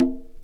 Conga Groovin 1.wav